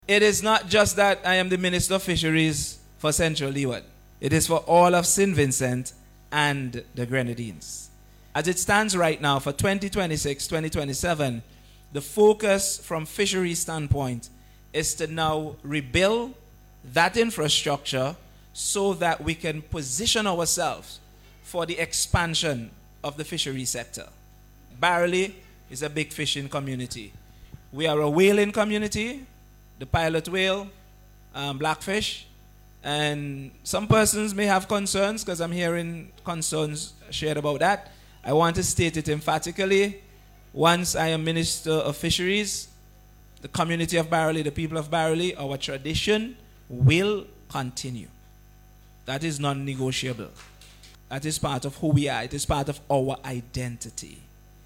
Speaking at a community consultation Thursday night in Barrouallie, Minister Huggins said the restoration of fisheries infrastructure is critical to the national economic development.